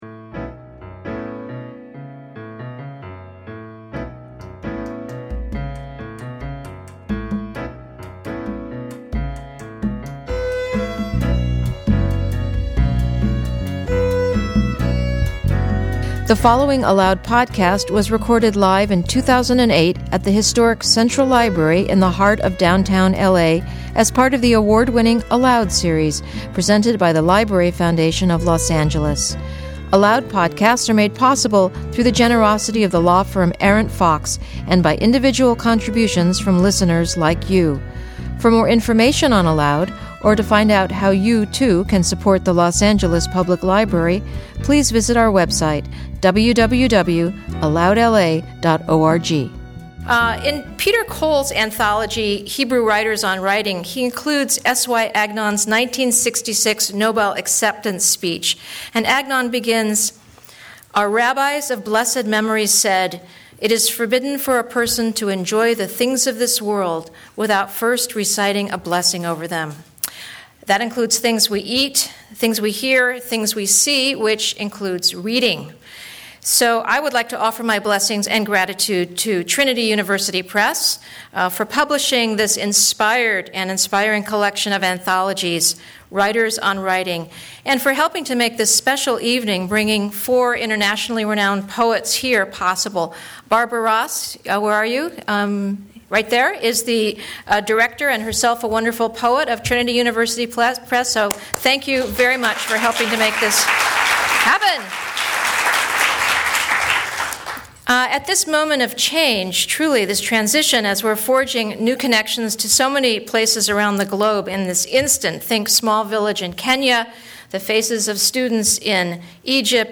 Discussing Hebrew, Polish, and Irish writers, four of the world's best known poets examine how local politics, national realities, and cultural traditions affect great literary traditions.